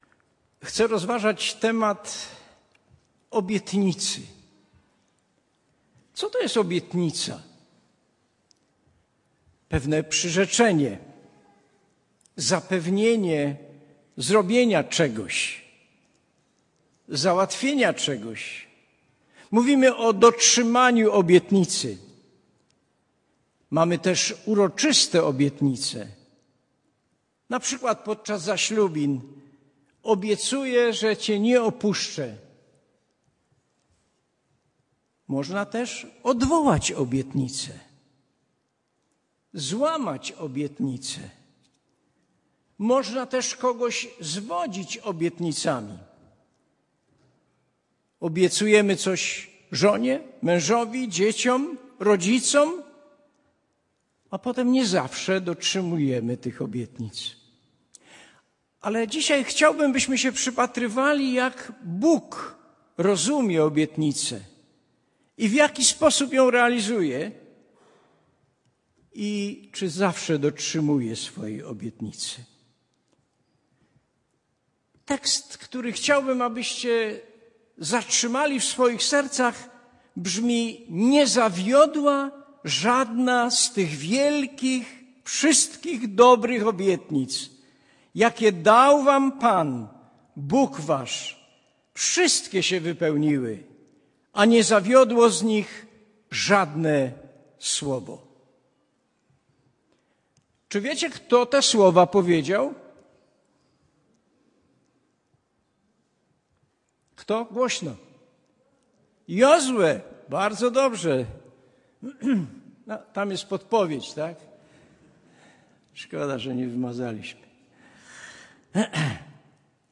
Kazanie